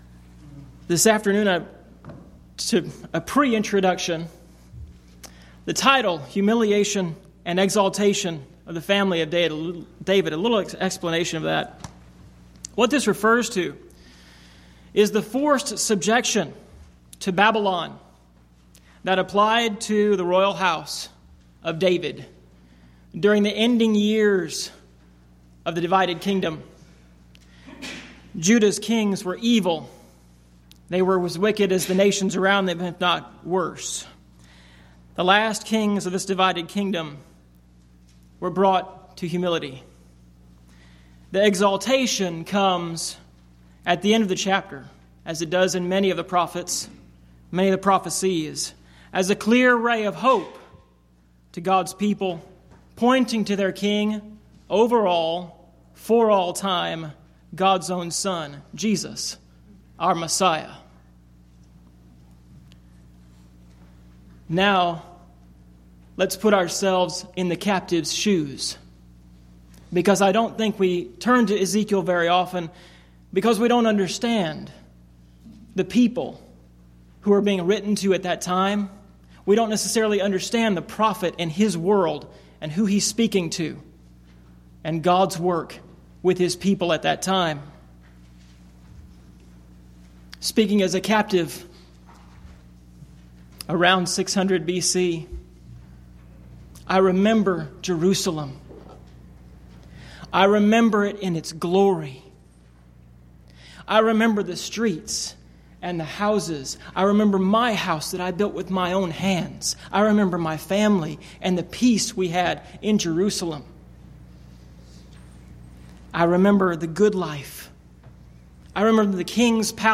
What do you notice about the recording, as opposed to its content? Event: 10th Annual Schertz Lectures Theme/Title: Studies in Ezekiel